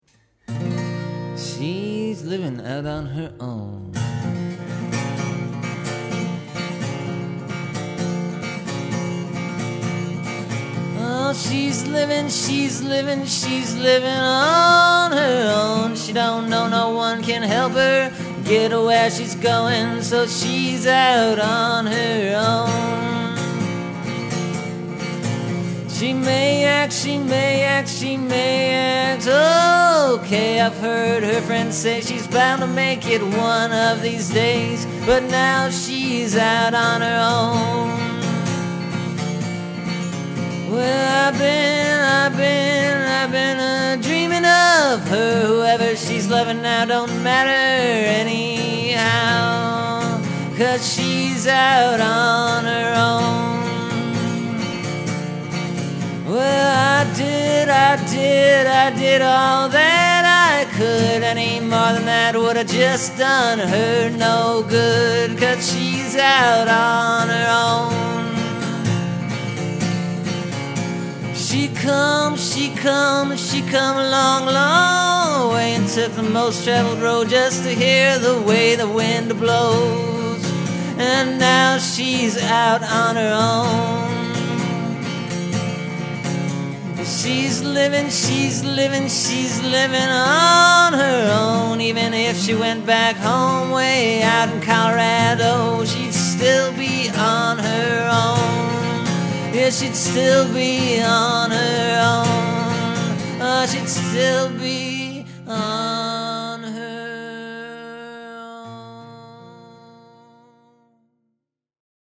It’s a little more in my own voice, which’s been easier to come by lately, rather than taggin along on the back of someone else.